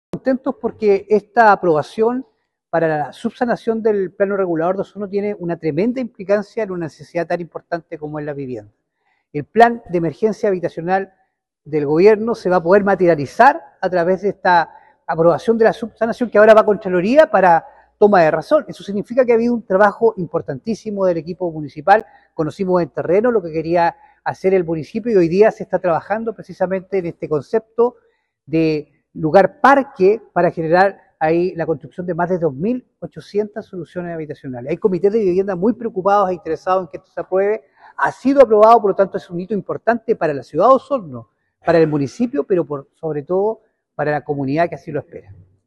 Por su parte, el consejero regional de la Provincia de Osorno, Francisco Reyes, valoró positivamente la subsanación al plano regulador, argumentando que este tipo de medidas son fundamentales para avanzar en la materialización del Plan de Emergencia Habitacional, el cual tiene como objetivo principal aliviar la escasez de viviendas en la región.